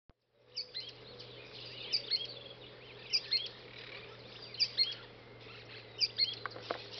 Pijuí Frente Gris (Synallaxis frontalis)
Nombre en inglés: Sooty-fronted Spinetail
Fase de la vida: Adulto
Localidad o área protegida: Parque Nacional El Palmar
Condición: Silvestre
Certeza: Fotografiada, Vocalización Grabada